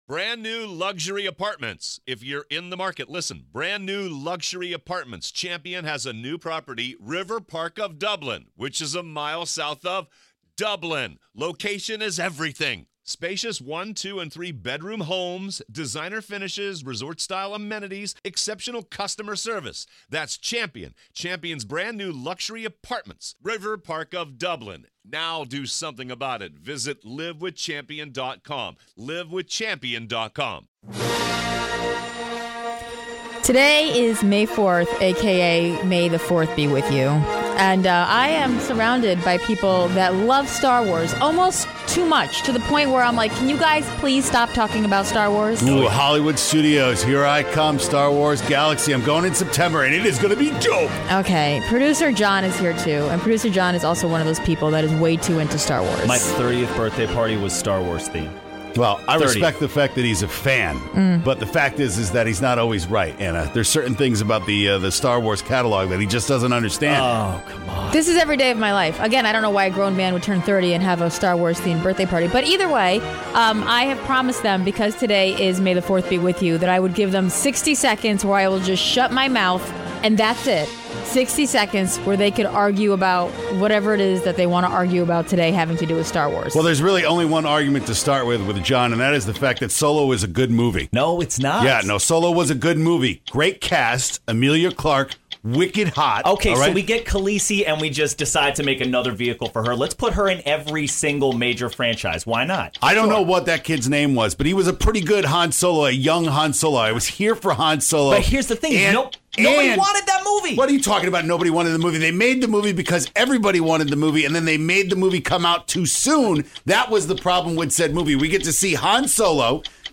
Teachers are calling in their craziest interactions with parents, and you won’t believe what they have to deal with!